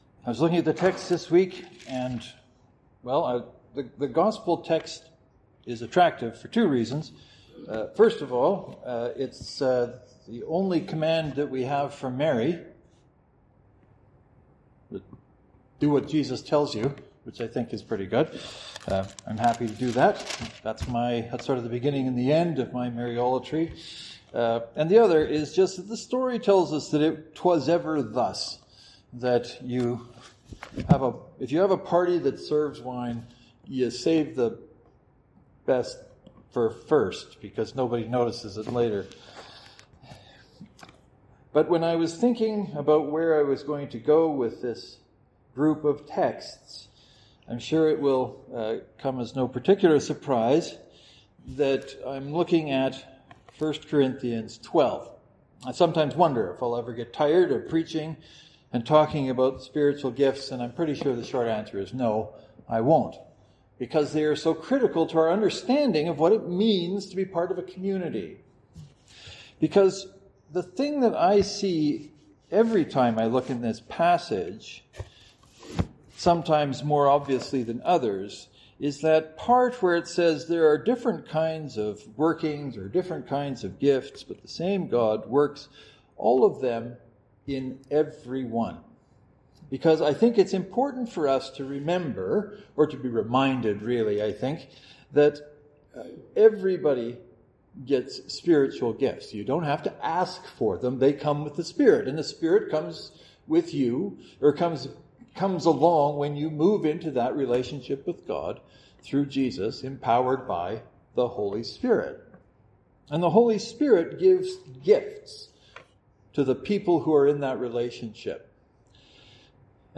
But if I’m aware of people who find gifts uncomfortable why might I think it is okay to title and then preach a sermon called “Gifts for everyone”?